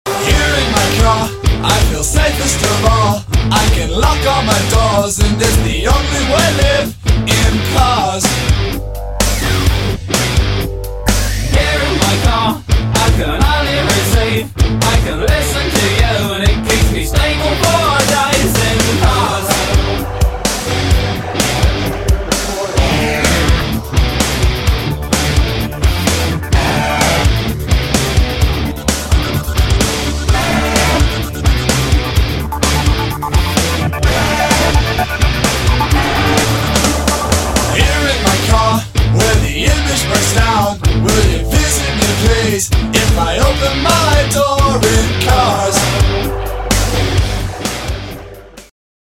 • Качество: 128, Stereo
OST